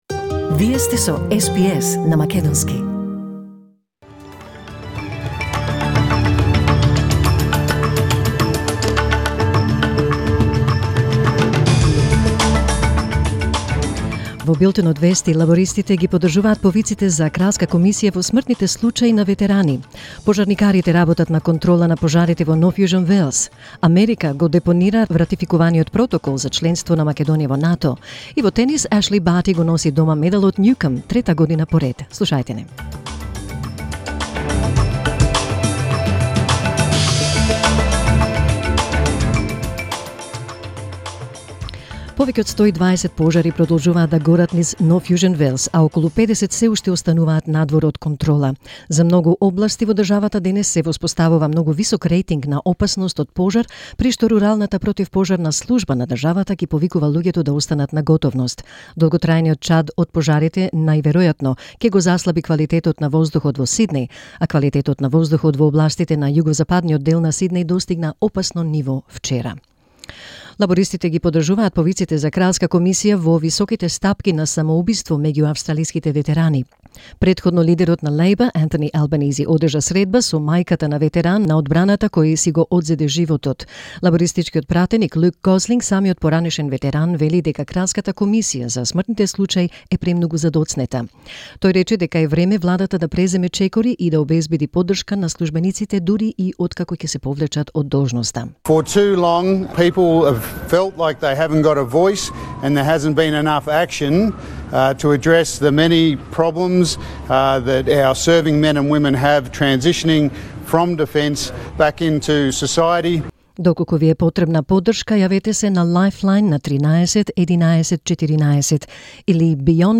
SBS News in Macedonian 3 November 2019